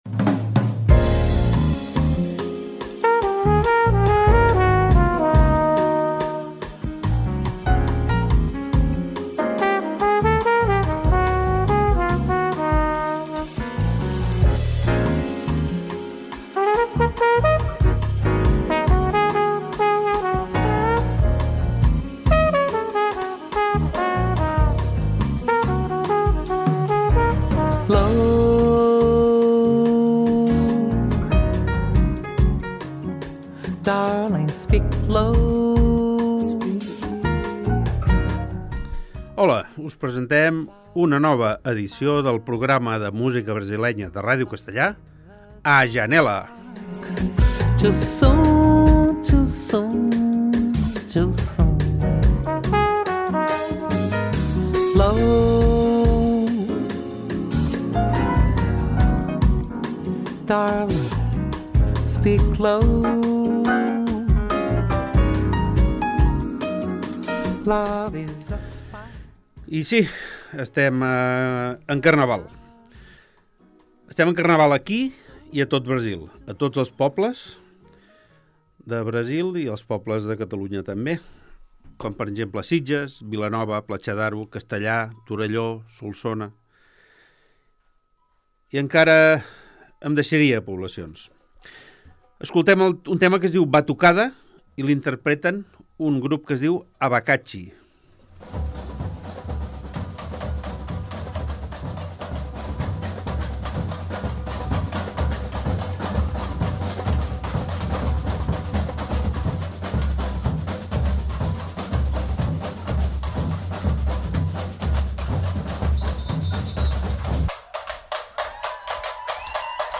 Programa de música brasilera